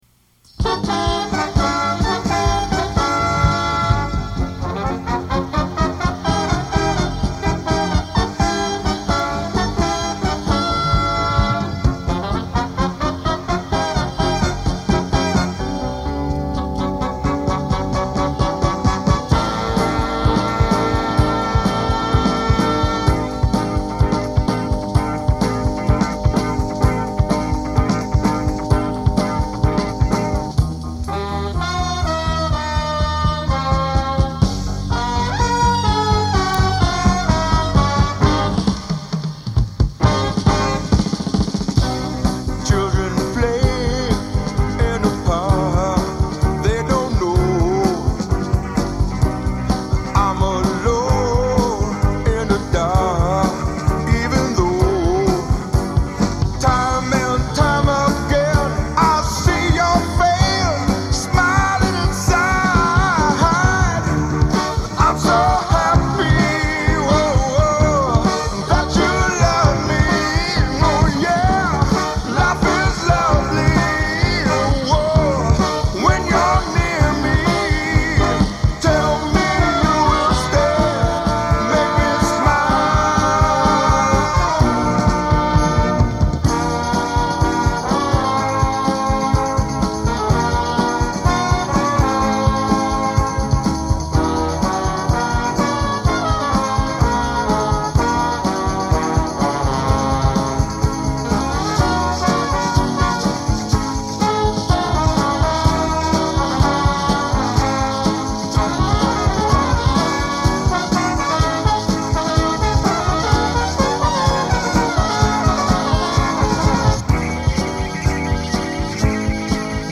I'm playing trombone.